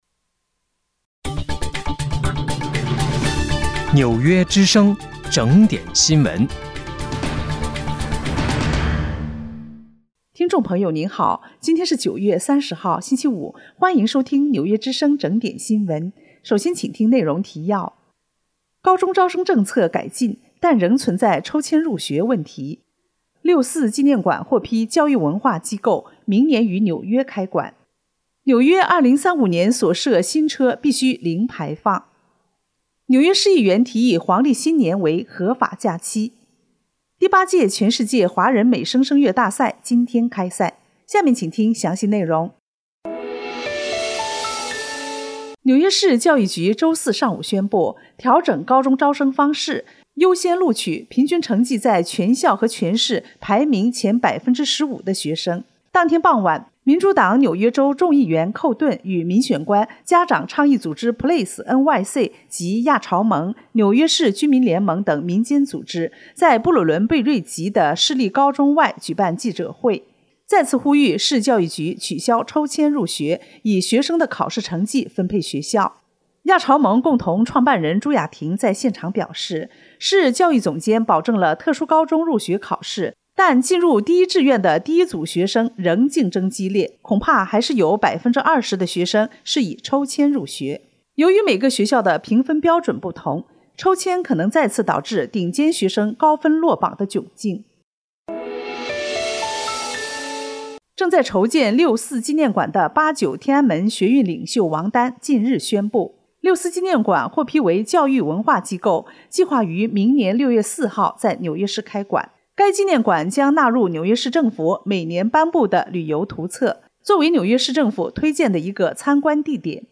9月30号（星期五）纽约整点新闻